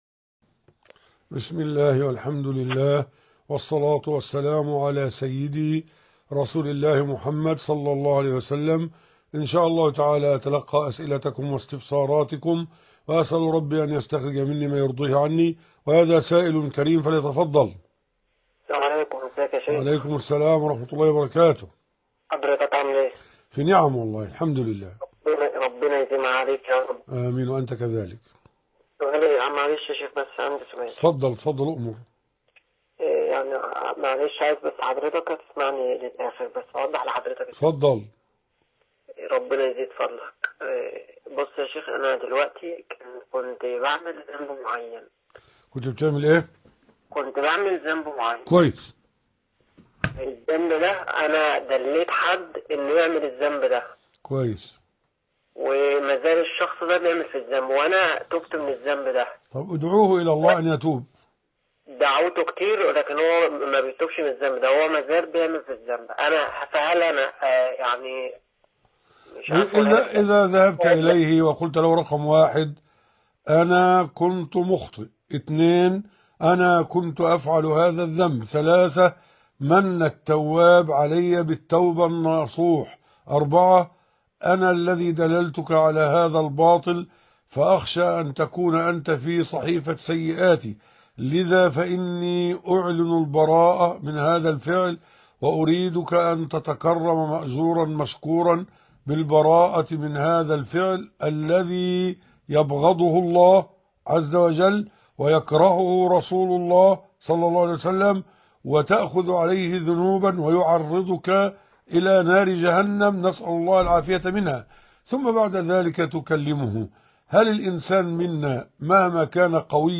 لقاء الفتاوى